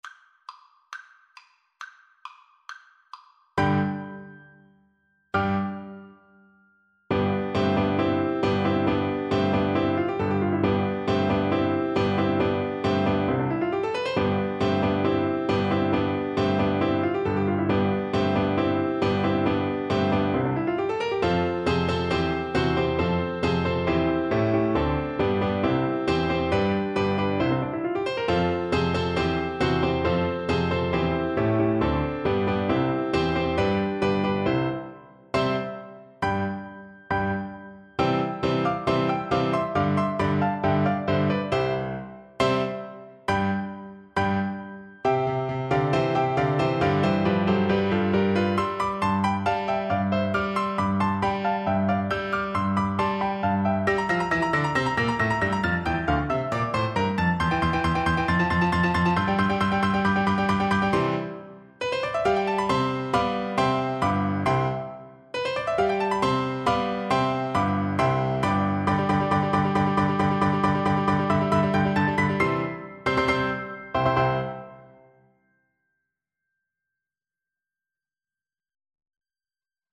2/4 (View more 2/4 Music)
Allegro con brio (=72) =68 (View more music marked Allegro)
Classical (View more Classical Flute Music)
Joyful Music for Flute